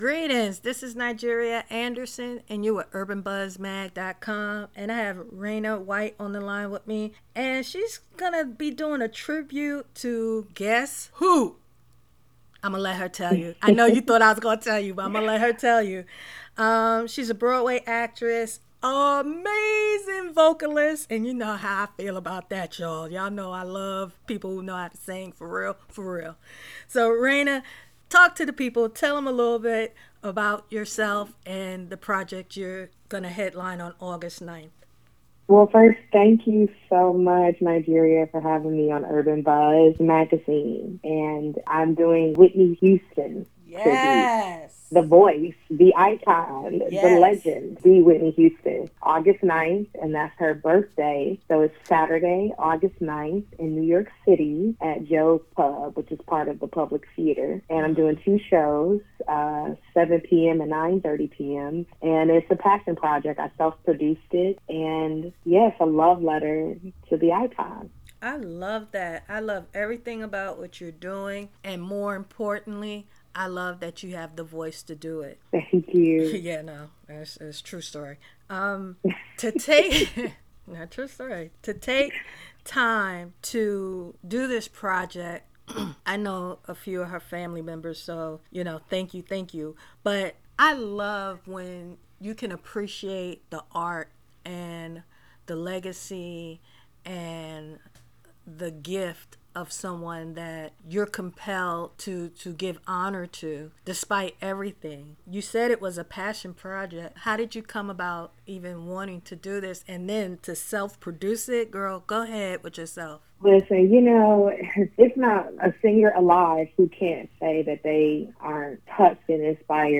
I want every aspiring entrepreneur to listen to our interview, especially if you desire to pursue your passion and have a family.